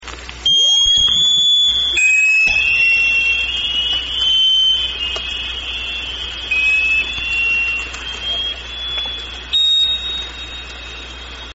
Individual sonar ping at 11:00 (1:14 into ~2hr 26 min recording)
Spectrogram of 0.5 second sonar pings at 3.25, 6.5, and 9.75 kHz recorded on the Orcasound hydrophone.